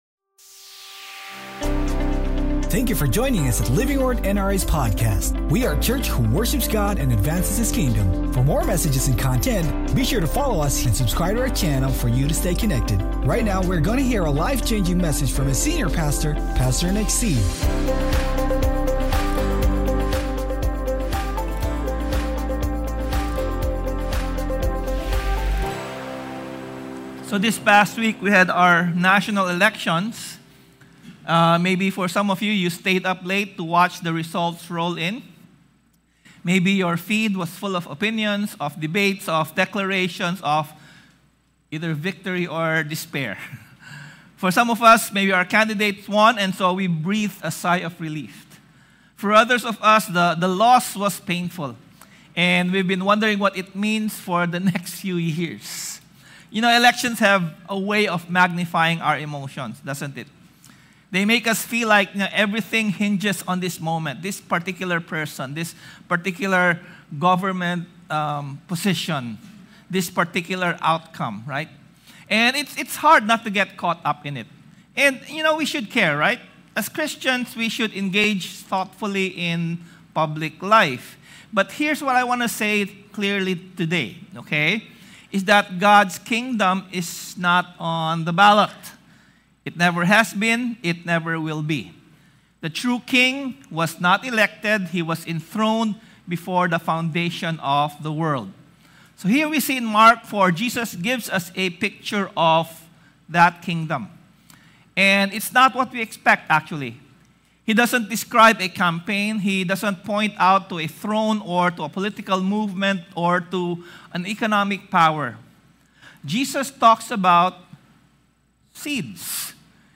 Sermon Title: THE HIDDEN KINGDOM